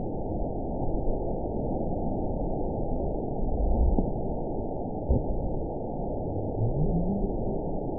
event 920563 date 03/30/24 time 03:44:01 GMT (1 year, 1 month ago) score 9.38 location TSS-AB04 detected by nrw target species NRW annotations +NRW Spectrogram: Frequency (kHz) vs. Time (s) audio not available .wav